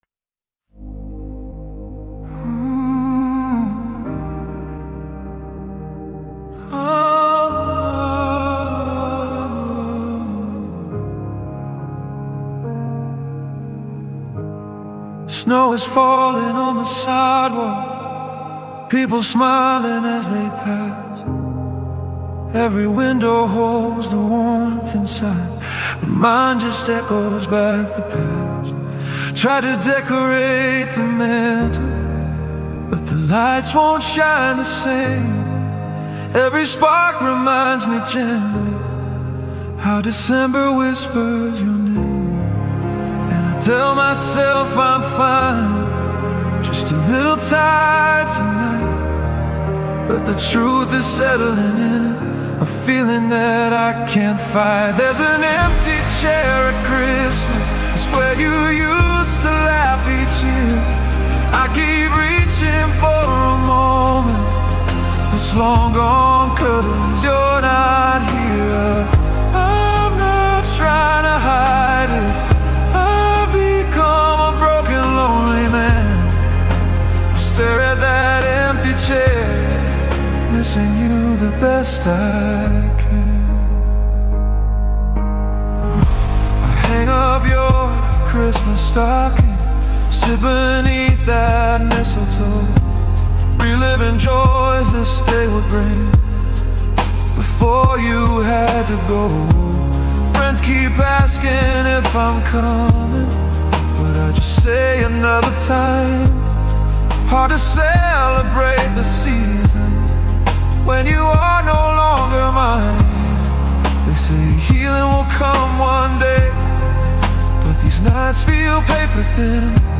For example, the "Christian Category" winner placed with a simple "vocal and keyboard only" recording.
Christian